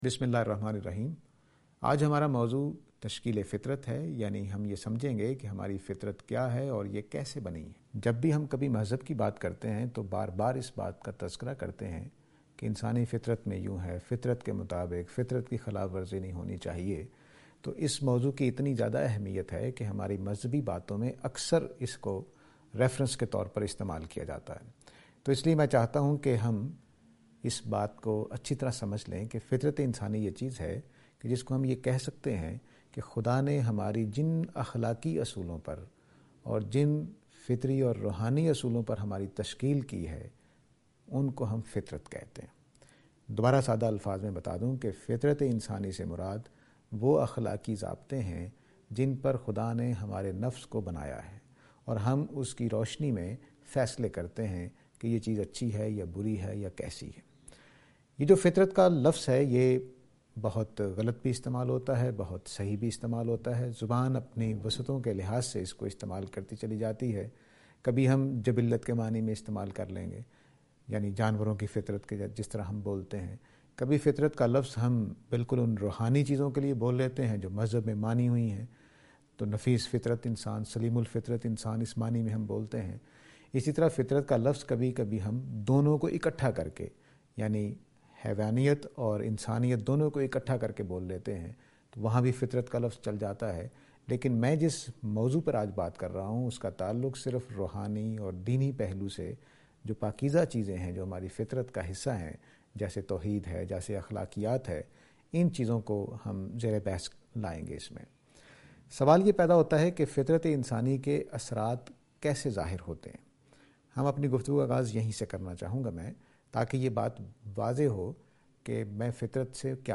Associate Speakers